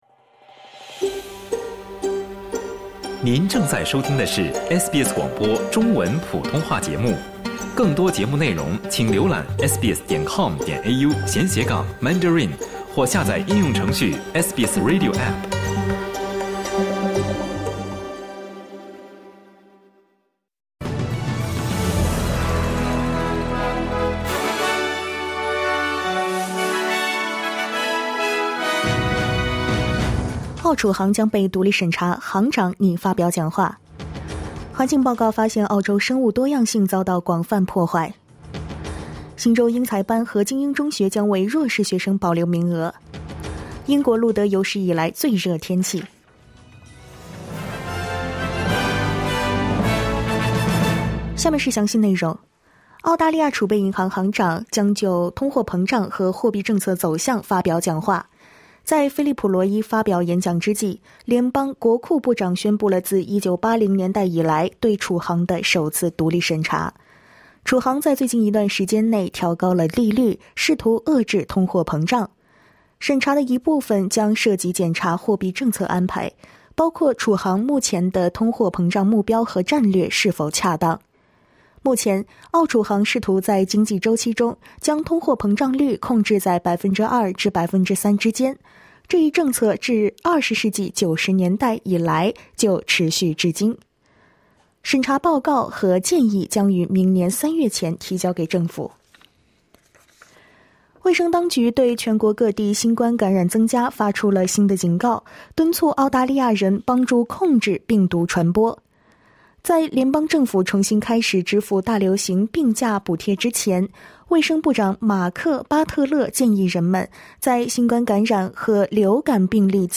SBS早新闻（7月20日）
请点击收听SBS普通话为您带来的最新新闻内容。